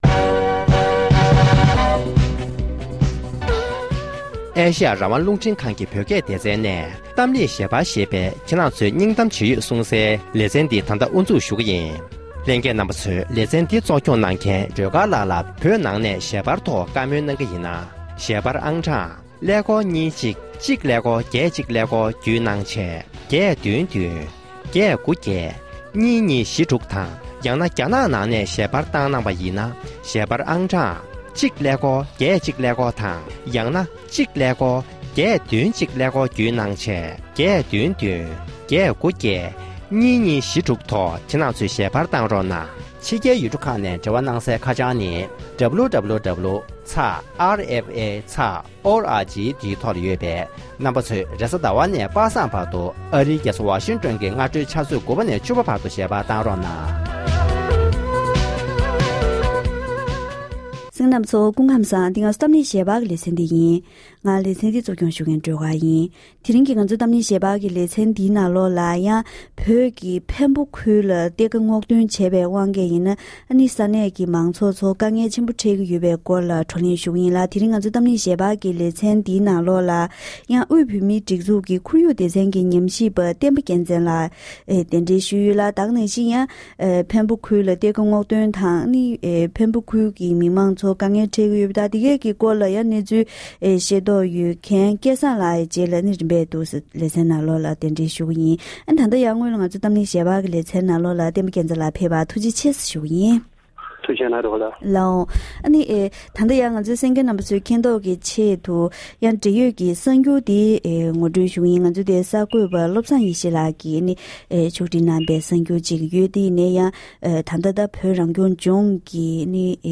༄༅༎དེ་རིང་གི་གཏམ་གླེང་ཞལ་པར་ལེ་ཚན་ནང་བོད་ཀྱི་འཕན་པོ་ཁུལ་དུ་རྒྱ་ནག་གཞུང་གིས་གཏེར་ཁ་བསྔོག་འདོན་བྱས་པའི་རྐྱེན་གྱི་ས་གནས་མང་ཚོགས་ཀྱི་འཕྲོད་བསྟེན་དང་འཚོ་གནས་ལ་དཀའ་ངལ་འཕྲད་ཀྱི་ཡོད་པ་མ་ཟད། ཁོར་ཡུག་ལ་ཡང་གཏོར་བཤིག་ཕྱིན་ཡོད་པའི་སྐོར་ལ་བགྲོ་གླེང་ཞུས་པ་ཞིག་གསན་རོགས་གནང་།།